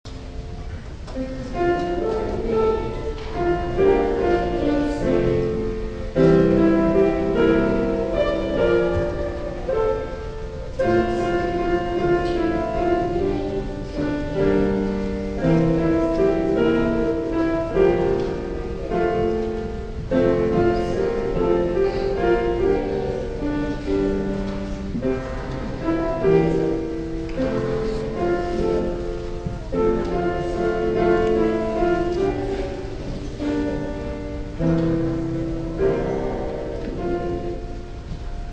Children Sang in Church
They had very soft voices today when they sang to their fathers in church. We need to get a good microphone system set up for next time.
Excerpt from Children Singing (mp3 file).